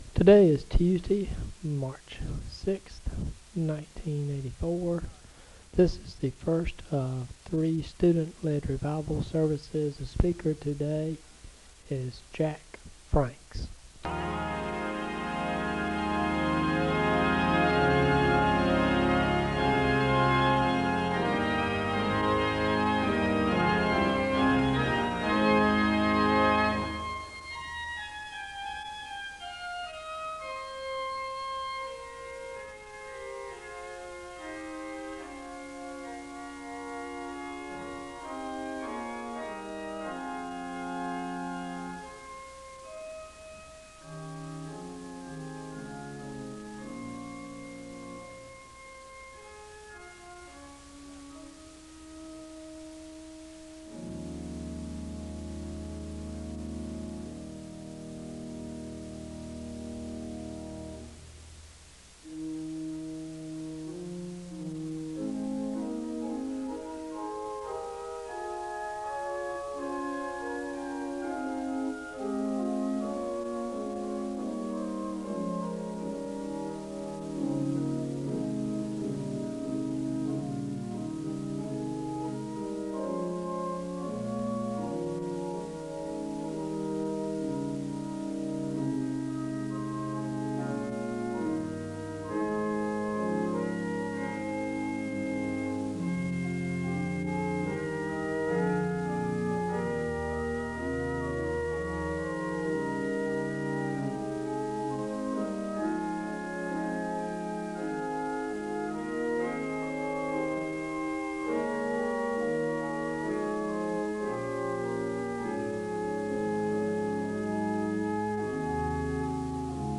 The service begins with organ music (00:00-03:24).
The speaker gives a call to worship from Matthew 6:33 & 7:7, and he sings a song of worship (03:25-06:48).
Evangelistic sermons